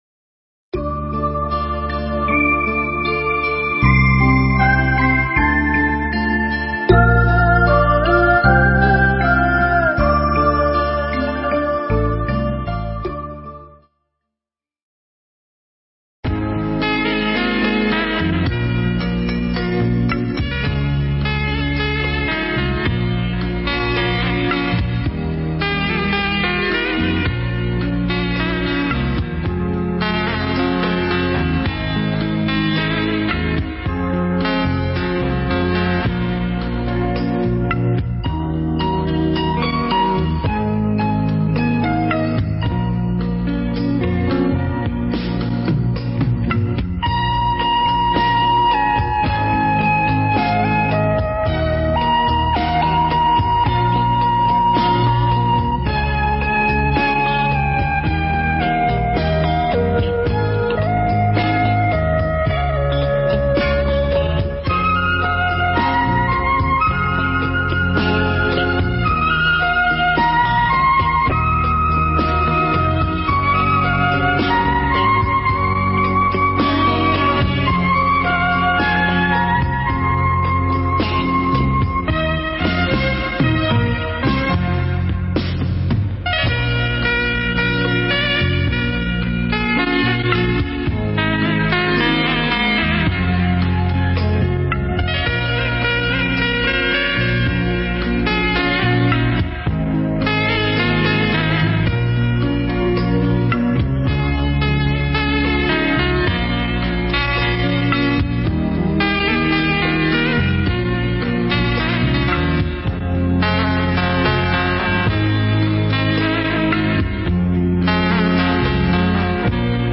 Mp3 Thuyết Giảng Kinh Bát Nhã 2 – Hòa Thượng Thích Thanh Từ giảng tại Chùa Quang, Vũng Tàu, ngày 27 tháng 6 năm 2000, (ngày 26 tháng 5 năm Canh Thìn)